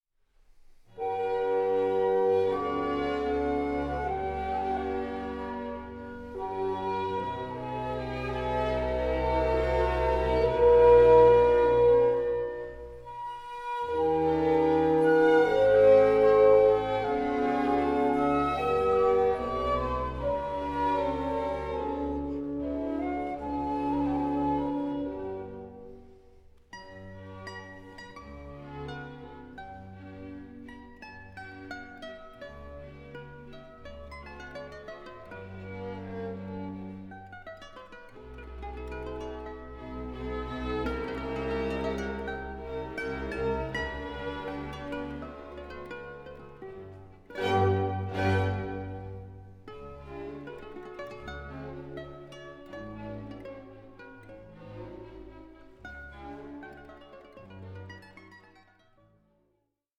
Larghetto and Rondo for Mandolin and Orchestra